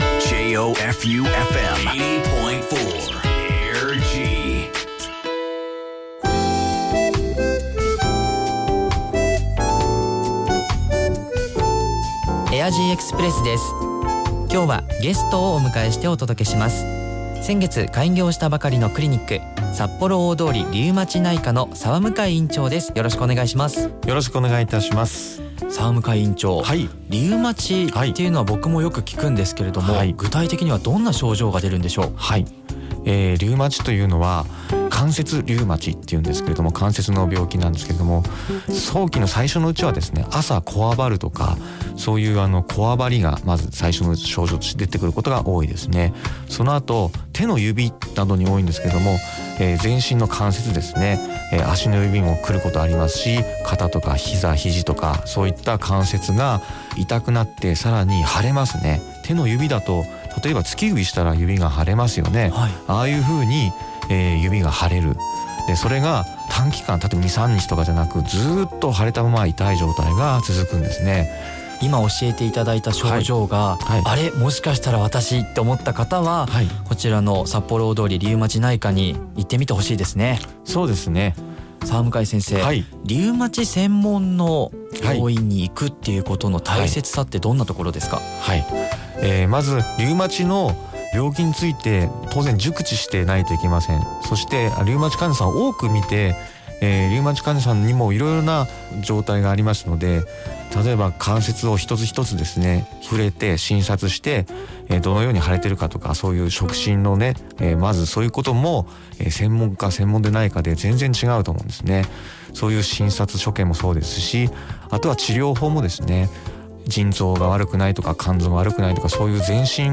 リウマチ 啓発ラジオ
リウマチ 啓発活動のため、2019年5/19と5/23にラジオに出演しました。リウマチについて分かりやすい言葉でお話ししたつもりです。
5/19放送分は5/14にスタジオで収録しました。 5/23は当院で中継が行われ、生放送でした。